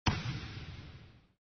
us_bounce.mp3